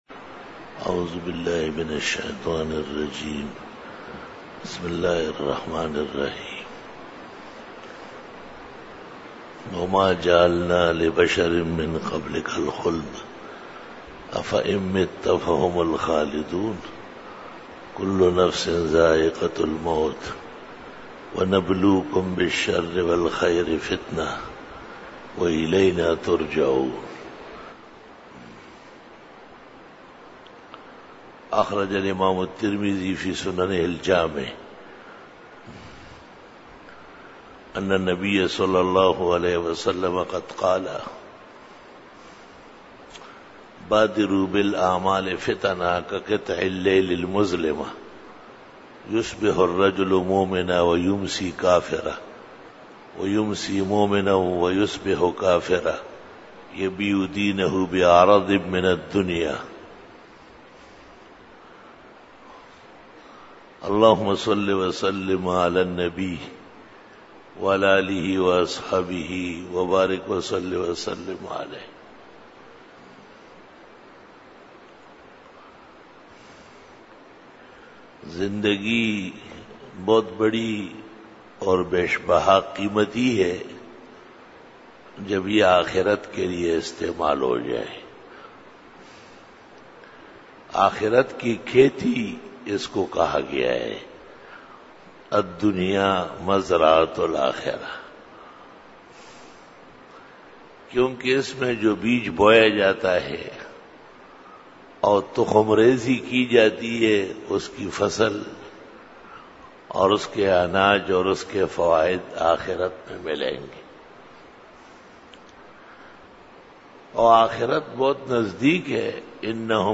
بیان جمعۃ المبارک
08:20 PM 257 Khitab-e-Jummah 2012 --